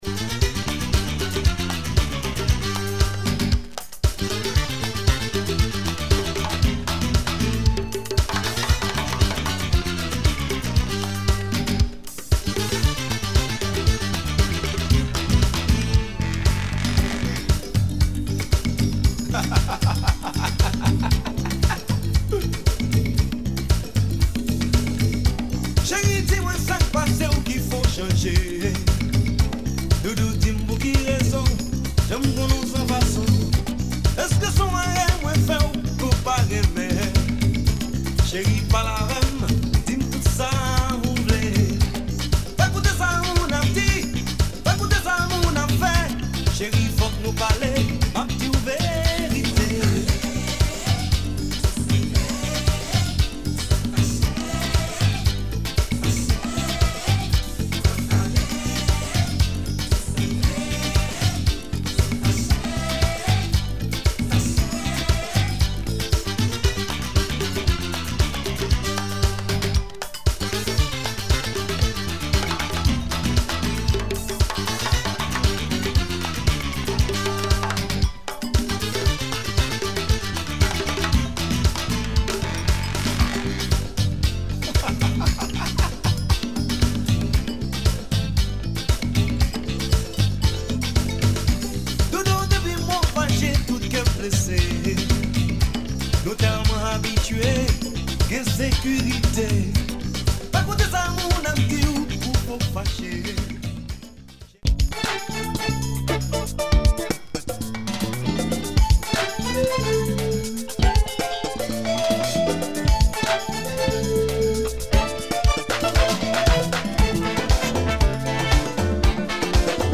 Caribbean dancefloor tunes
In demand modern rara music recorded in the USA.